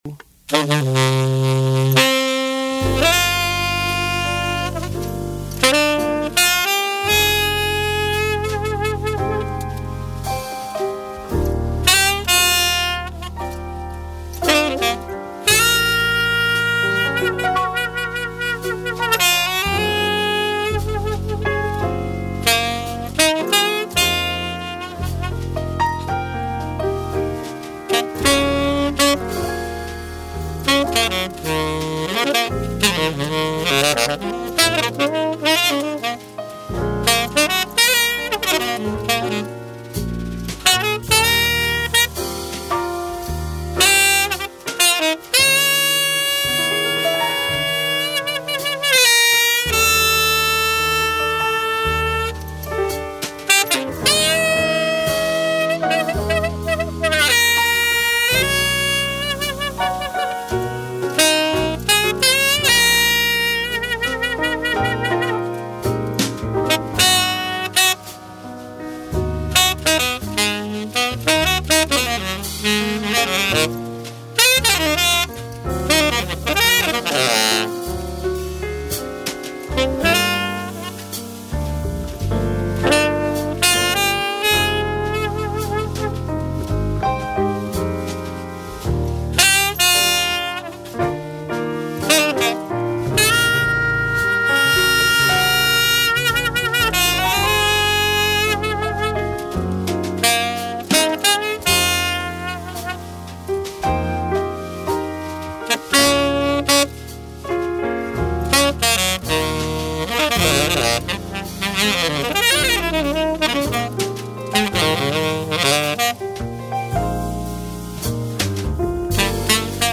Click on the button above to download a play-test (then check your "Downloads" folder and select "Open" or "Save As") of the Selmer Bach 300 Tenor Saxophone & Yibuy Golden Jazz 7 Mouthpiece